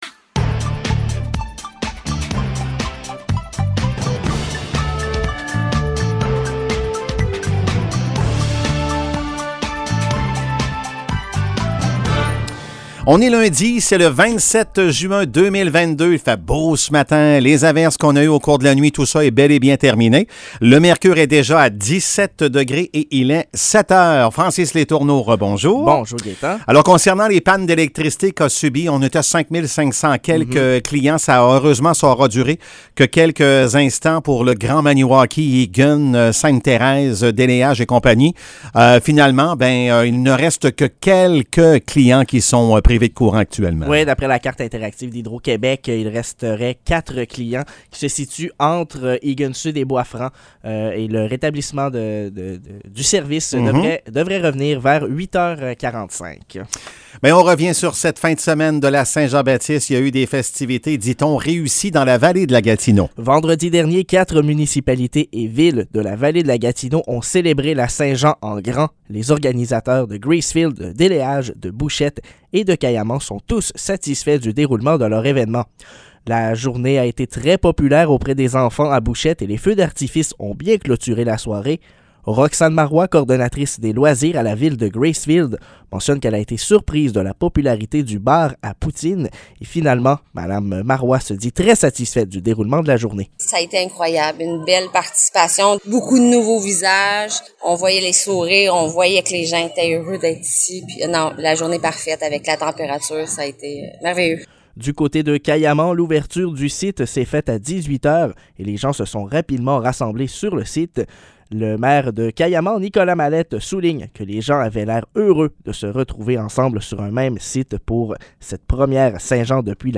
Nouvelles locales - 27 juin 2022 - 7 h